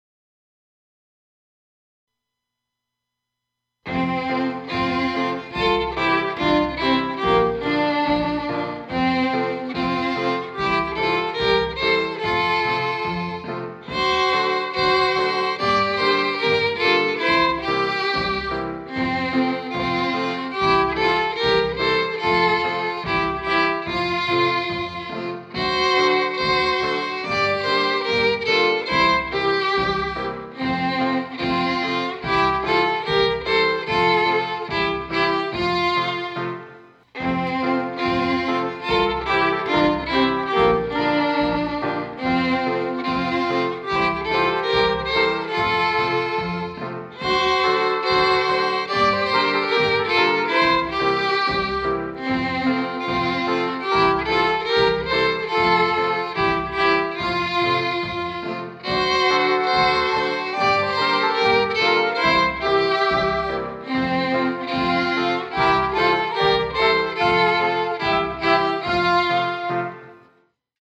Versione cantata